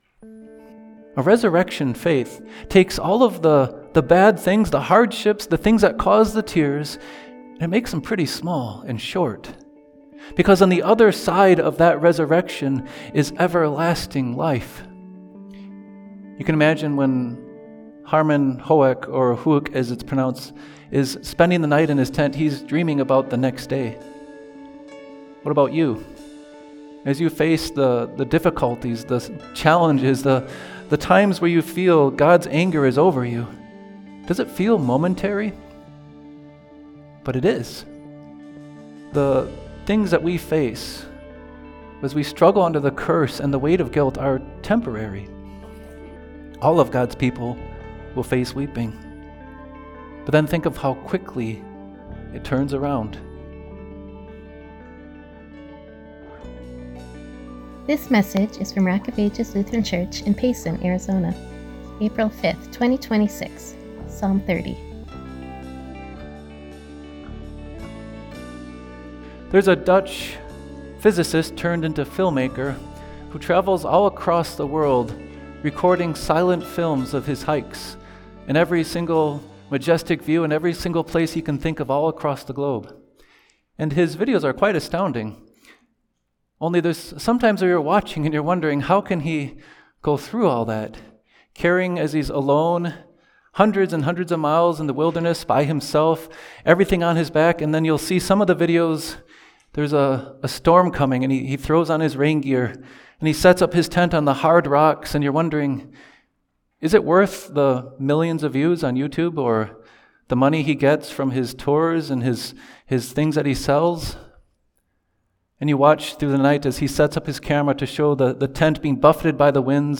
Psalm 30 ● April 5, 2026 ● Series -Banner is Love ● Listen to sermon audio